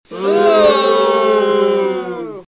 起哄的声音不满意哦的音效免费下载素材
SFX音效